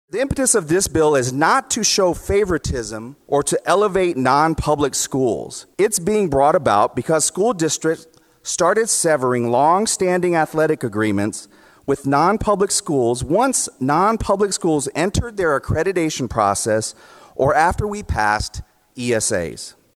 During House debate on the bill, Stone said kids should be allowed to compete in sports, whether they're enrolled in a public or a private school.